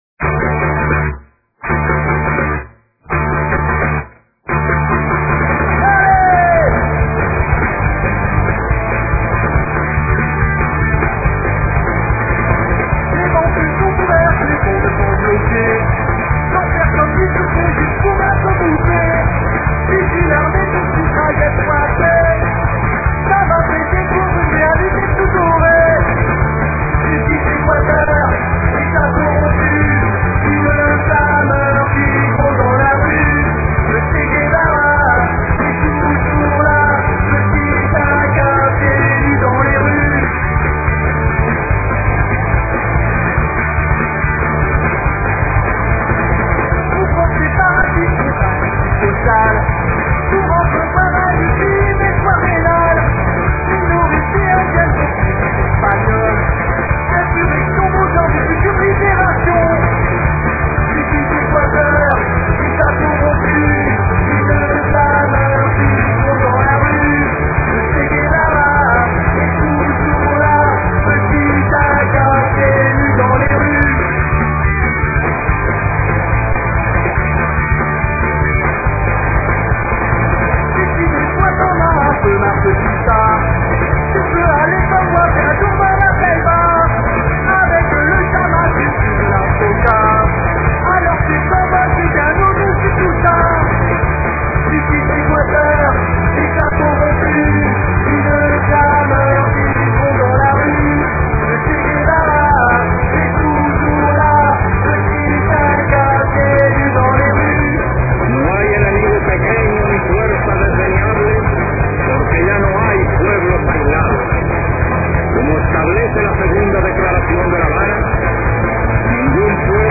Les fichiers sont en mp3 et de qualité assez mauvaise .